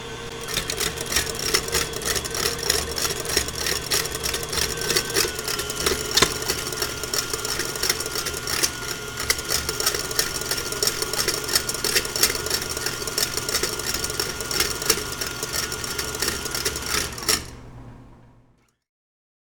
Кухня звуки скачать, слушать онлайн ✔в хорошем качестве